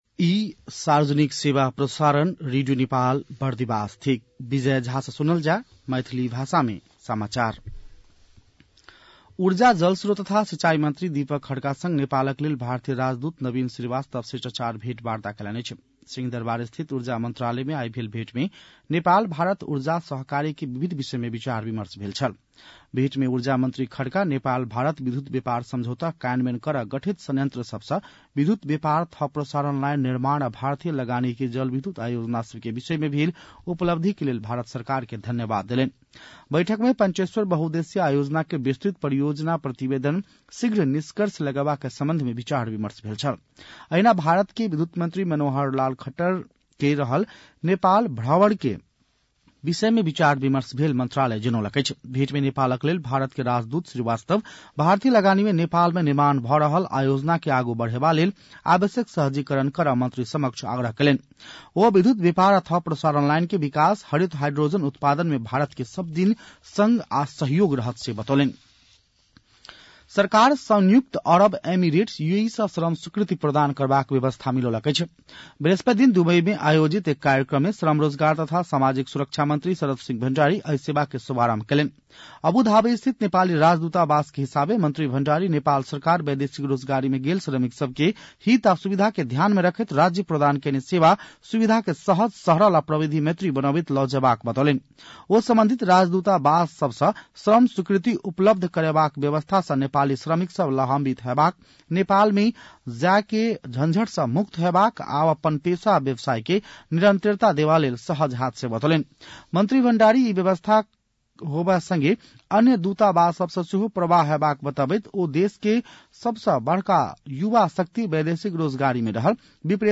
An online outlet of Nepal's national radio broadcaster
मैथिली भाषामा समाचार : ३ फागुन , २०८१